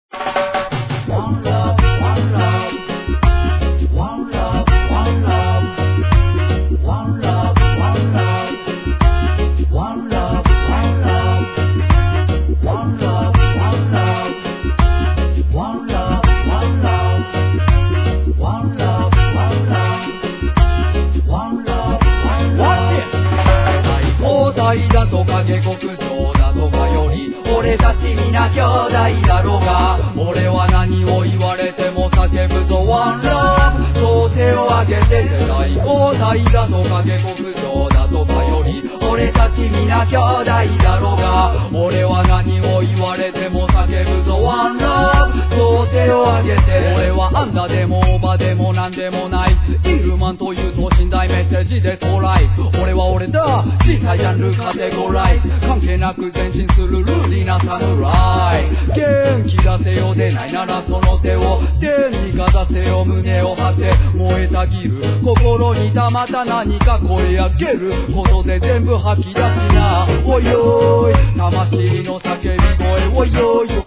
JAPANESE REGGAE